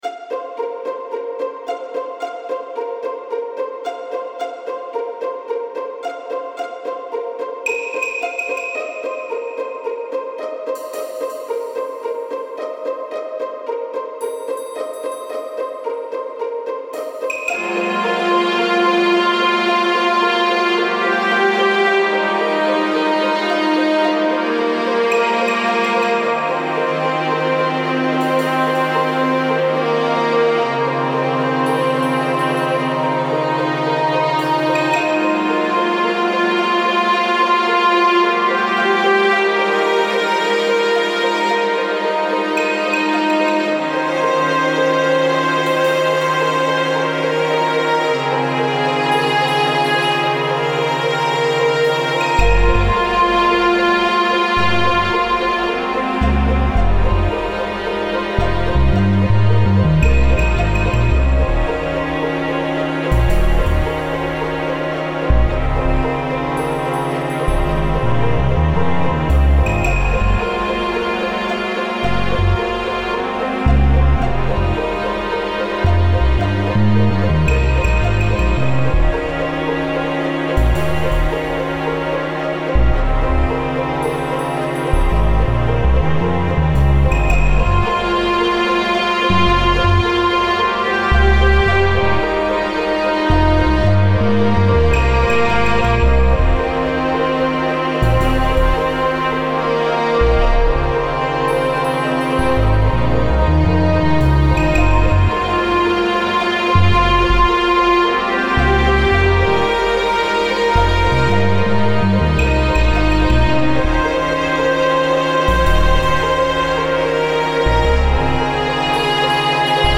弦楽器とシンセベースで厳しくも幻想的な雰囲気を出しました